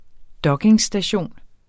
Udtale [ ˈdʌgeŋsdaˌɕoˀn ]